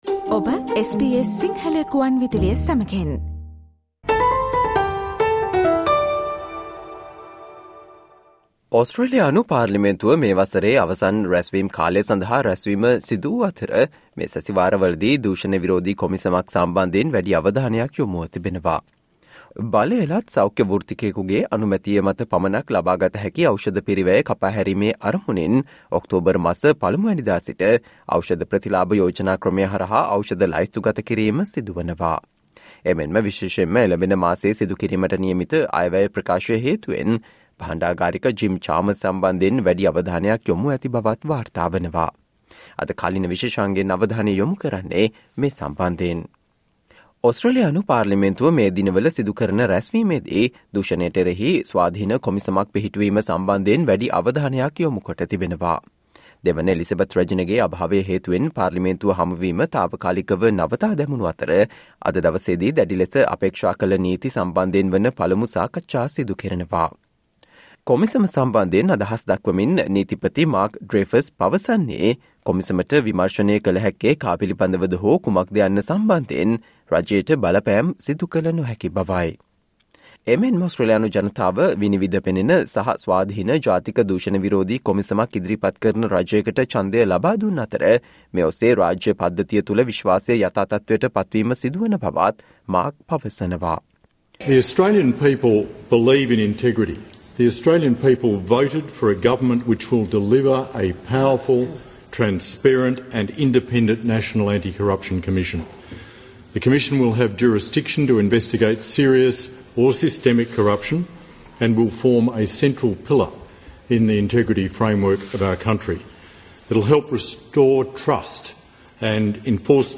Today - 27 September, SBS Sinhala Radio current Affair Feature on Parliament's return tackles cost of living, integrity, fuel and cyber security